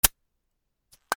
ビンのふたを開ける 炭酸